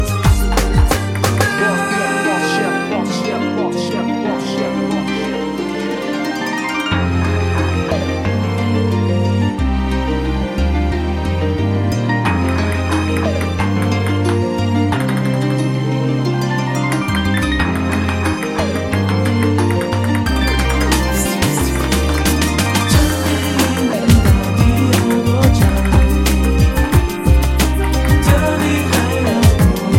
高音质立体声带和声消音伴奏